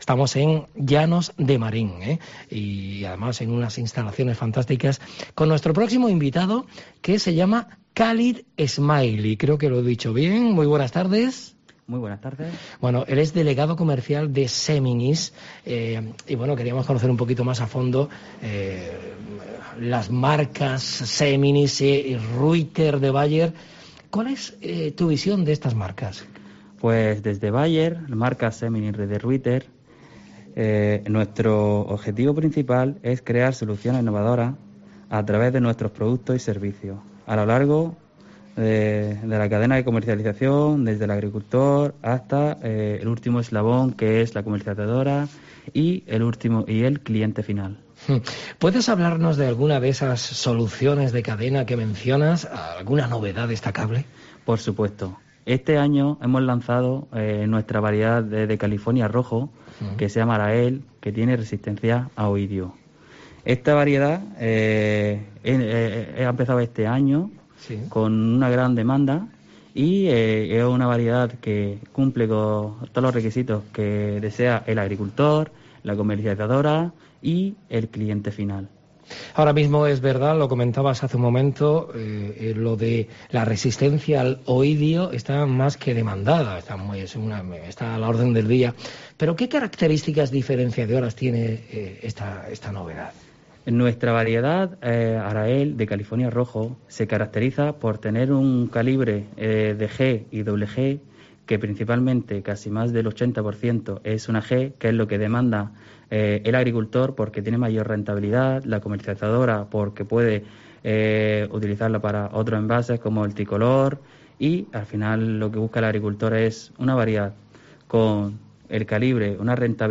La empresa alemana ha participado en la I Semana de la agricultura en Roquetas de Mar, organizada por COPE Almería.